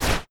Impact2.wav